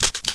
switch_burst.ogg